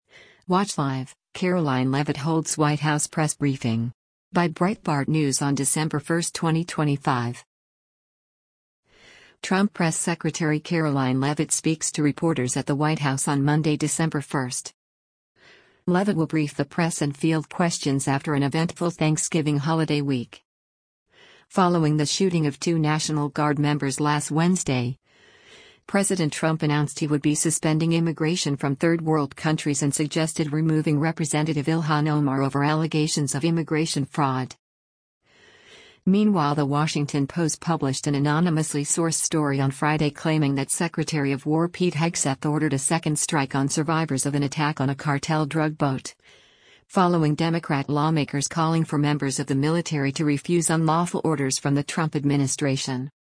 Trump Press Secretary Karoline Leavitt speaks to reporters at the White House on Monday, December 1.
Leavitt will brief the press and field questions after an eventful Thanksgiving holiday week.